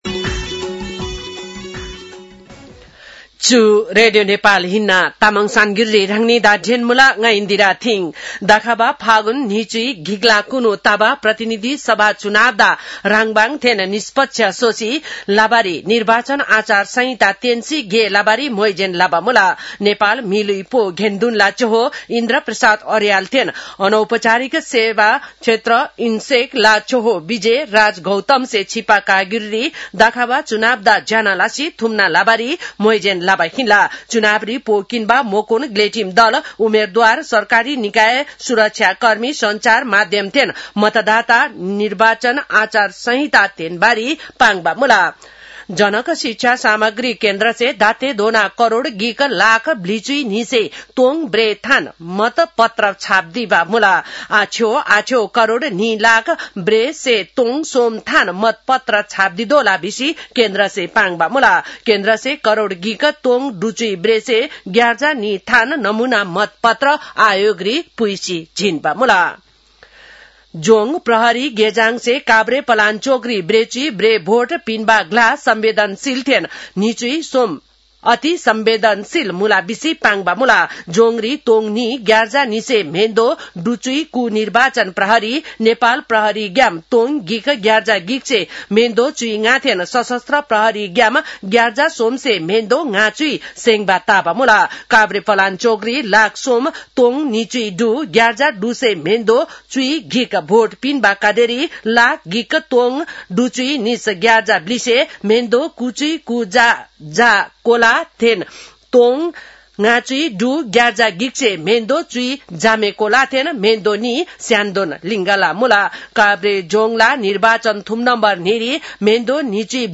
तामाङ भाषाको समाचार : ९ माघ , २०८२
Tamang-news-10-09.mp3